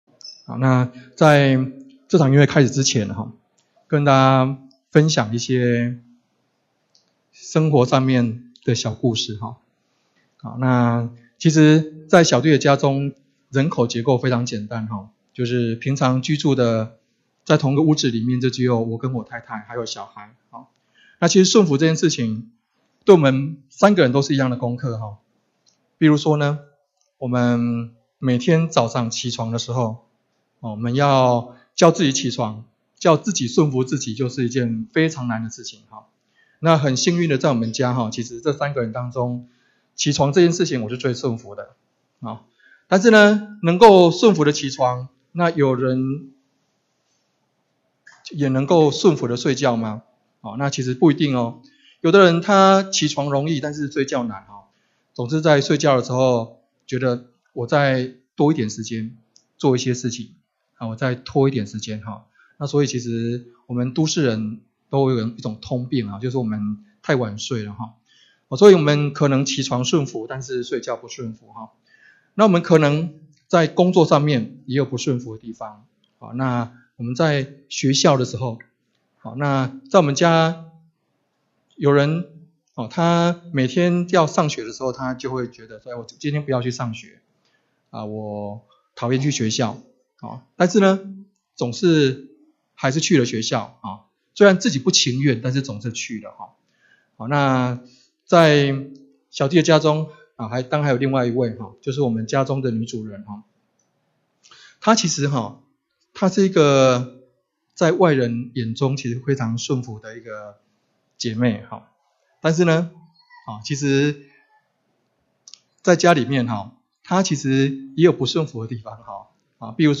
2018年8月份講道錄音已全部上線